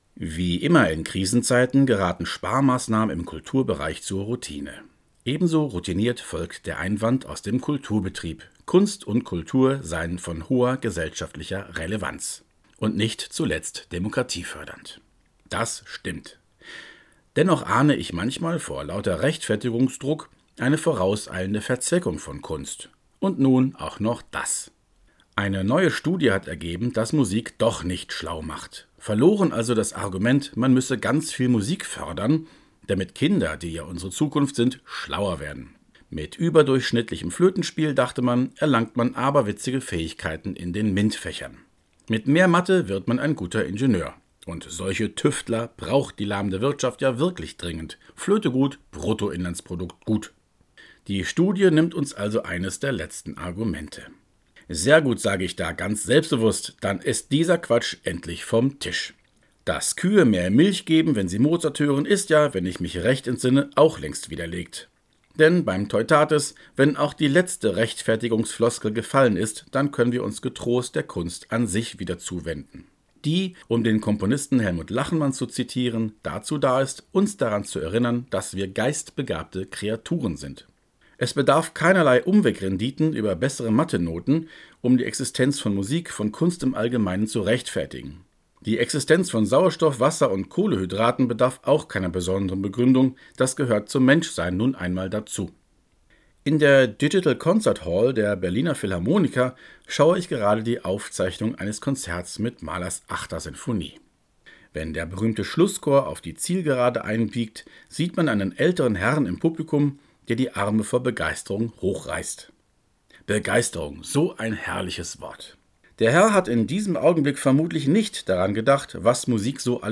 Glosse